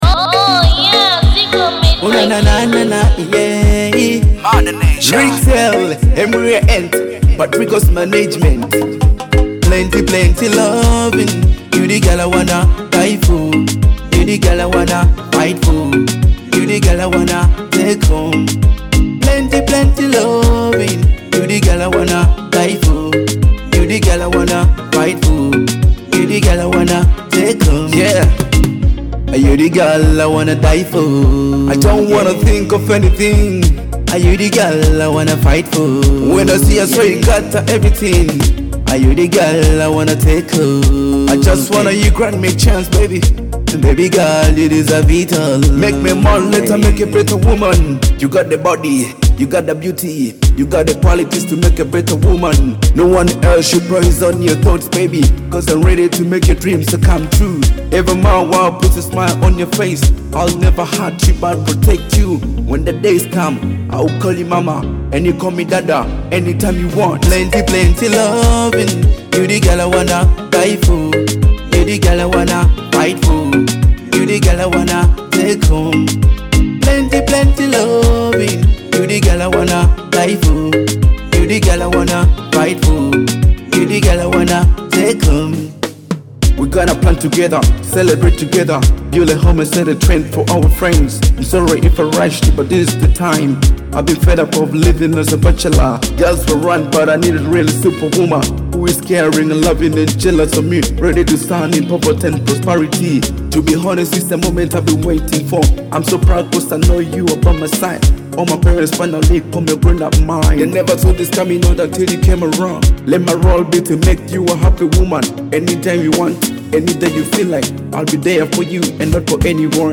a love hit with smooth vocals and infectious melodies.